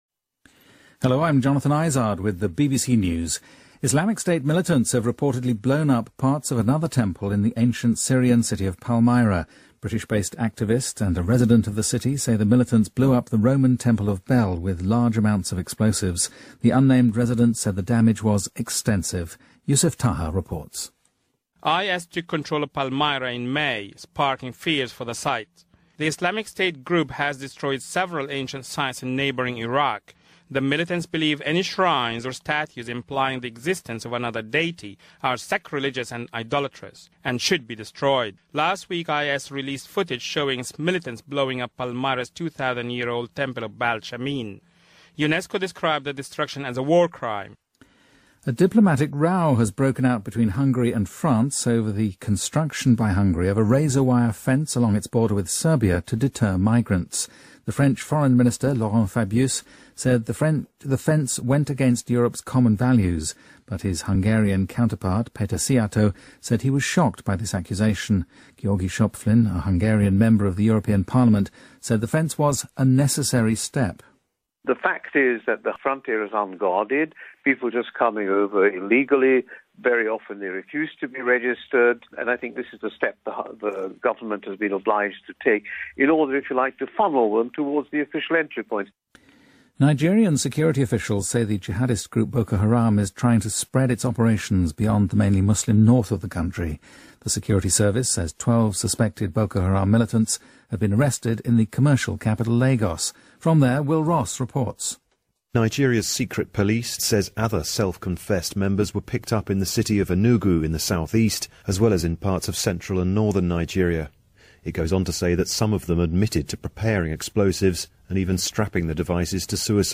BBC news,马来西亚总理纳吉布拒绝辞职